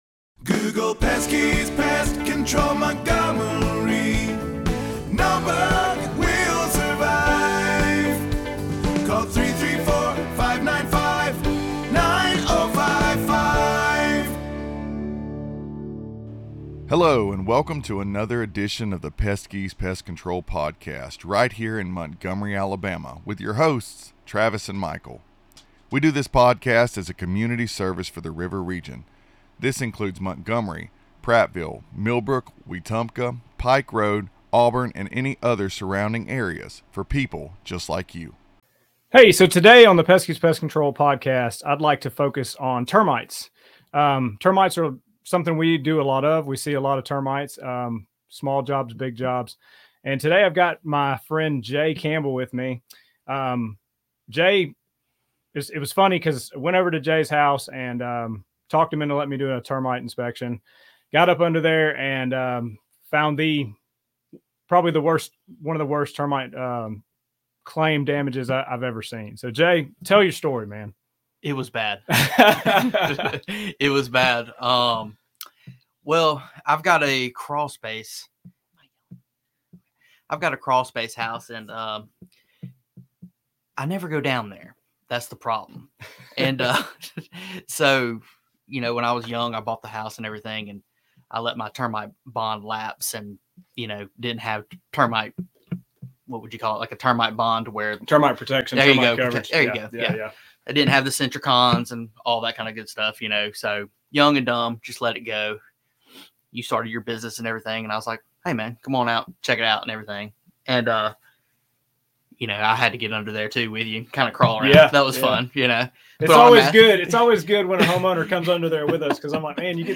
Peskies Interviews a Customer with Severe Termite Damage in Montgomery Alabama!